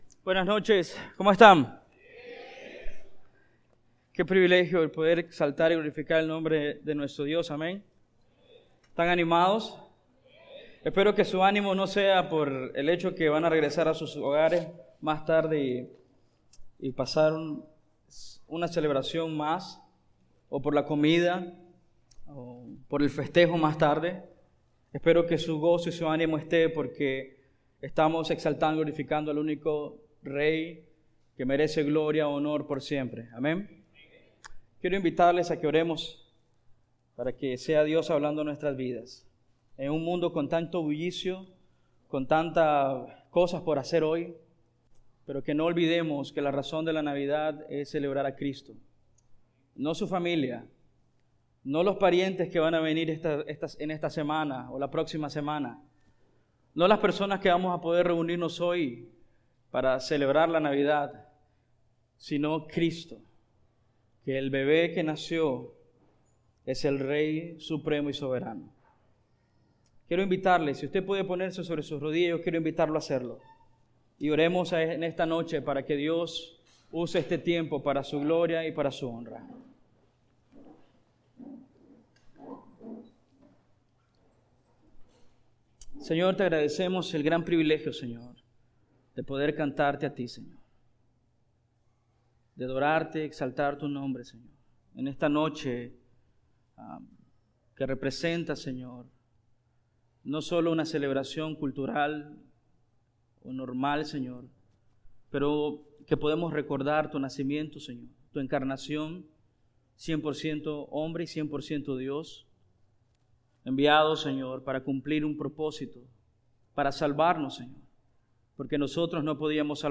Serie de sermones: Venid y Adoremos al Rey Categoria: Vida Cristiana Idioma: es | Siguiente